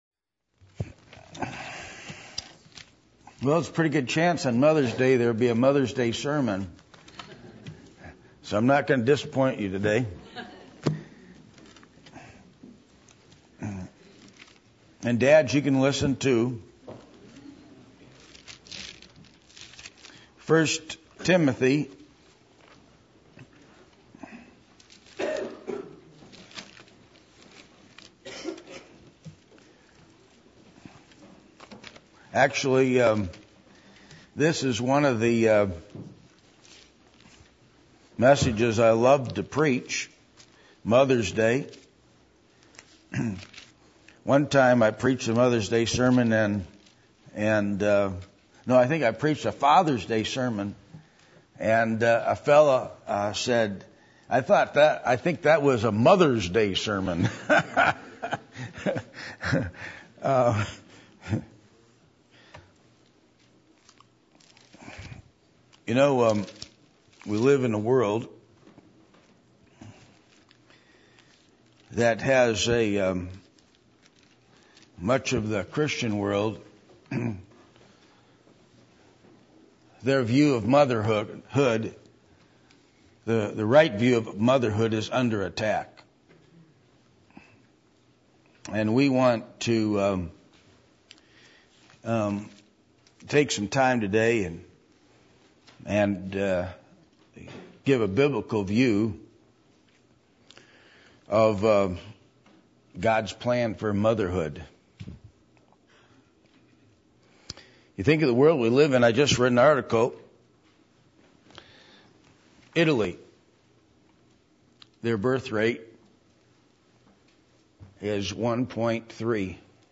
1 Timothy 2:9-15 Service Type: Sunday Morning %todo_render% « Baptism/Baptism of the Spirit Cont.